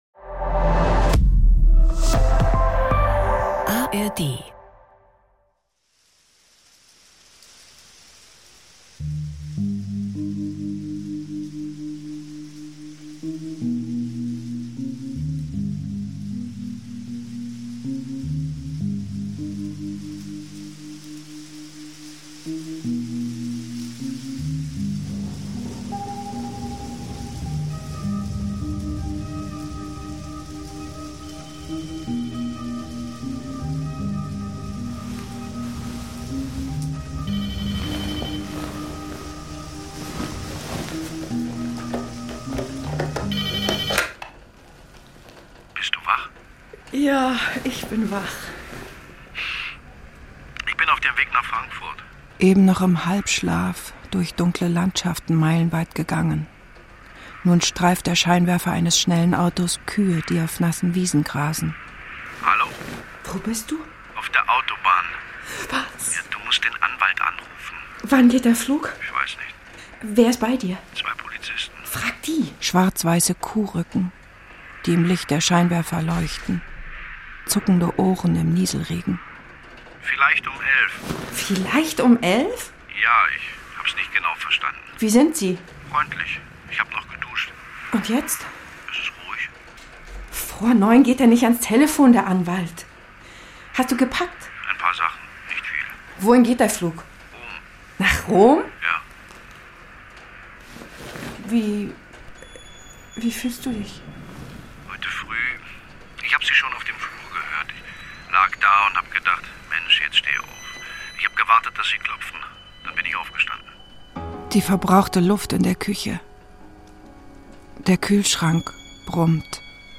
Eine semidokumentarische Flüchtlingsgeschichte, lakonisch und gleichzeitig poetisch, erzählt aus der Perspektive des afrikanischen Mannes und der deutschen Frau.